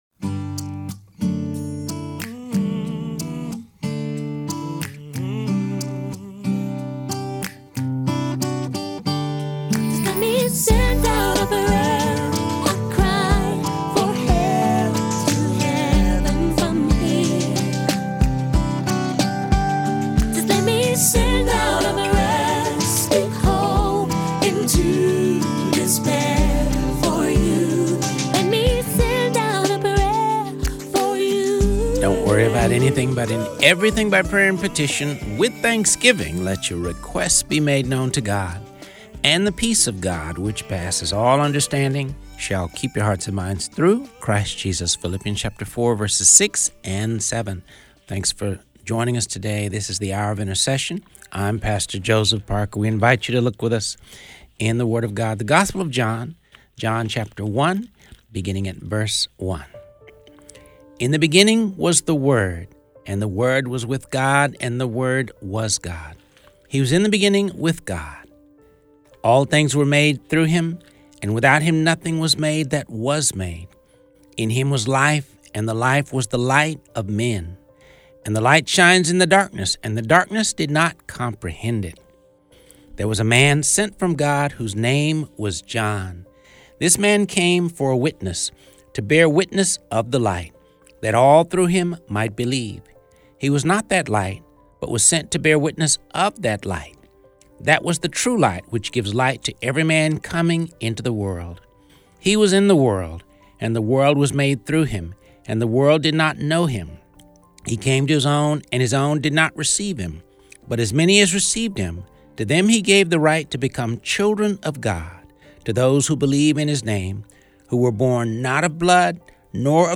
Reading through the Word of God, ep. 224